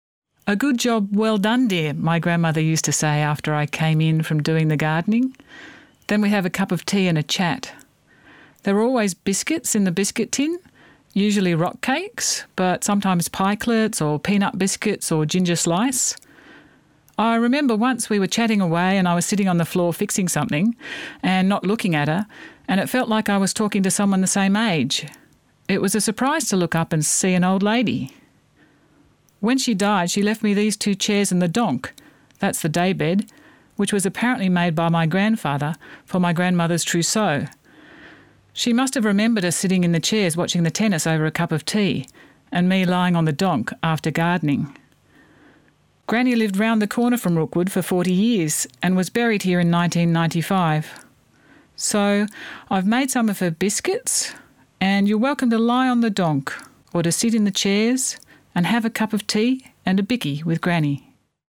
I wrote the short sound piece below for the audio guide, which in my case people could listen to as part of the work.